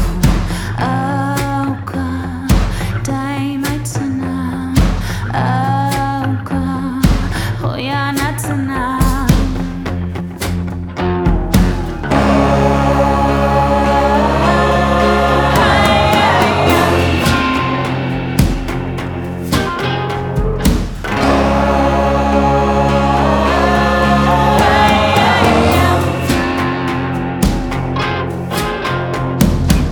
• Folk